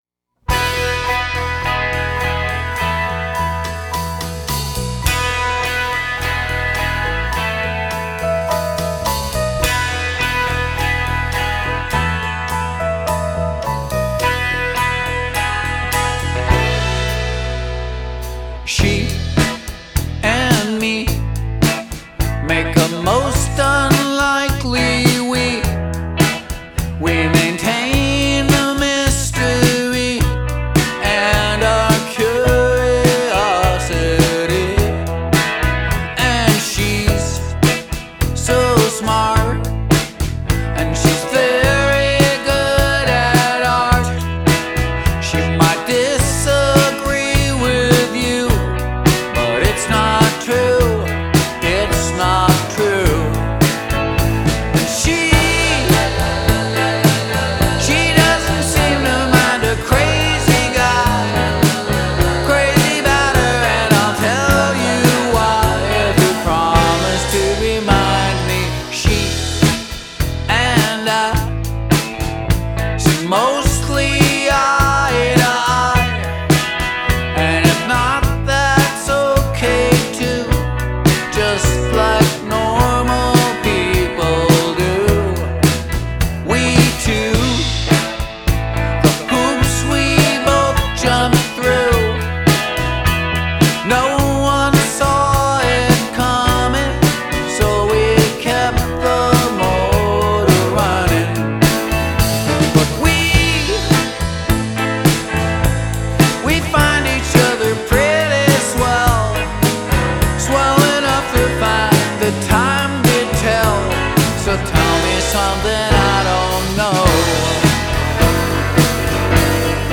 power-pop
bass
drums
electric guitar